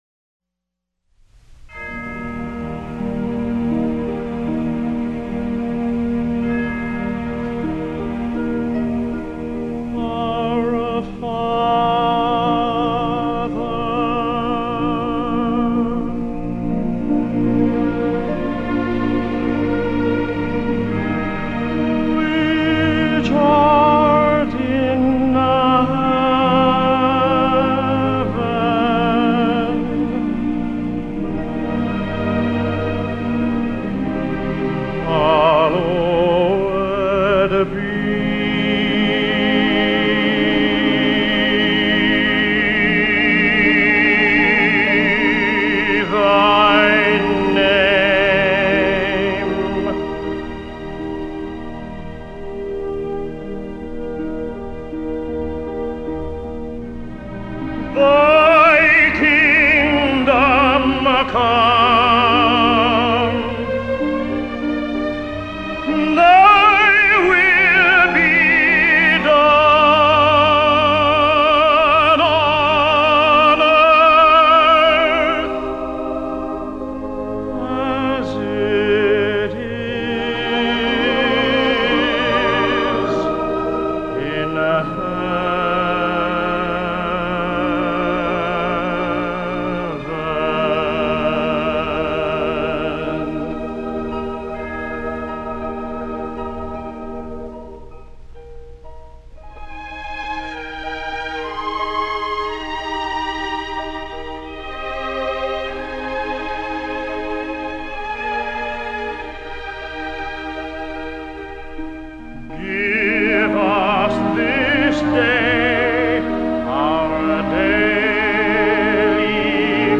текст на рус. и англ. яз. с фортепианным сопровождением  pdf